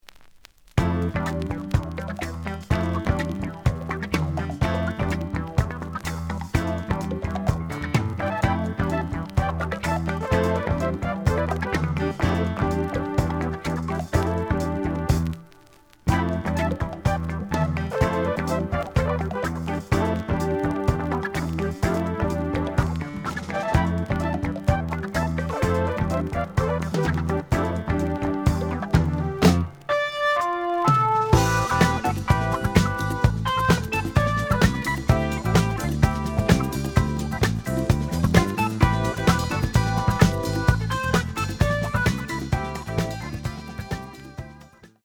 The audio sample is recorded from the actual item.
●Genre: Jazz Rock / Fusion